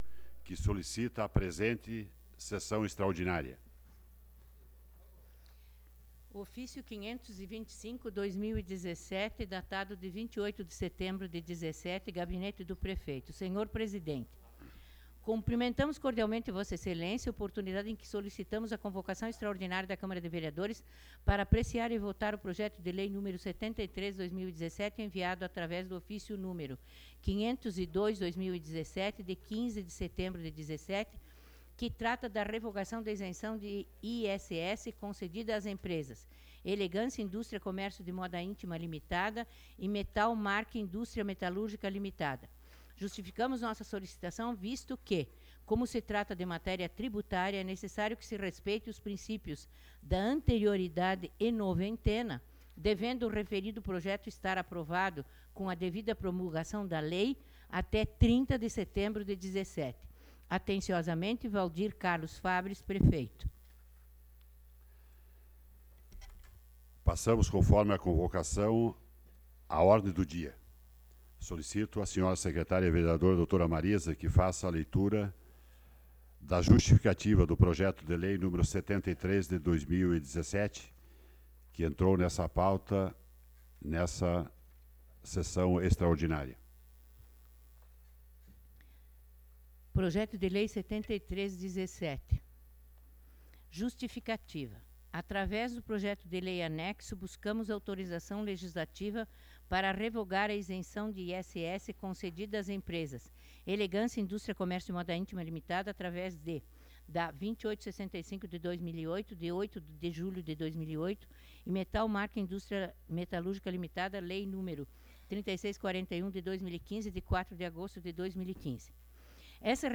Sessão Ordinária do dia 29 de Setembro de 2017